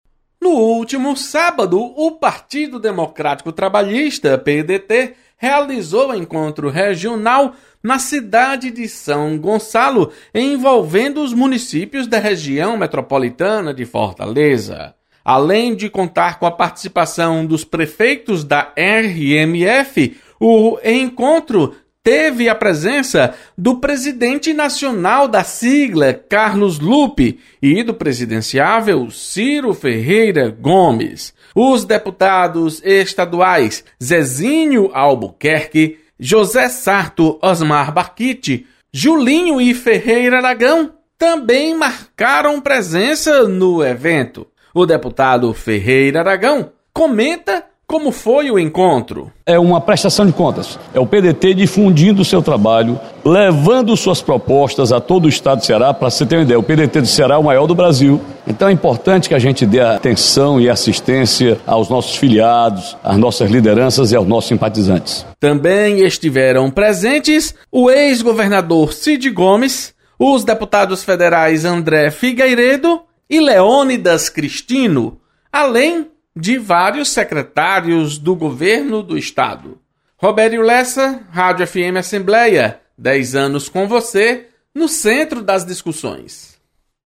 Deputado Ferreira Aragão ressalta importância de encontro do PDT realizado no último sábado. Repórter